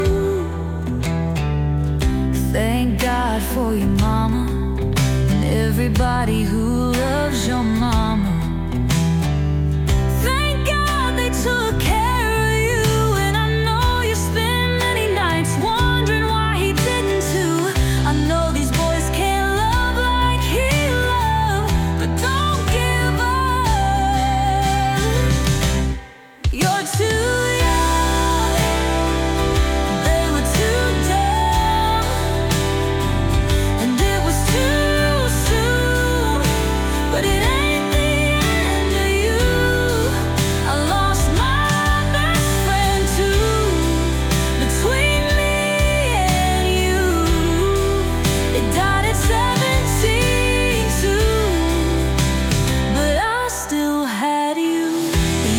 Demo Engels